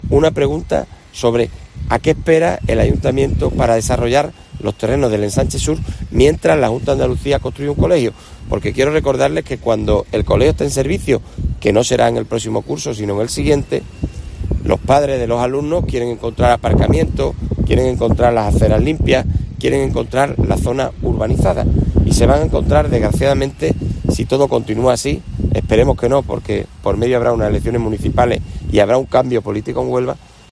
Jaime Pérez, portavoz del PP en el Ayuntamiento de Huelva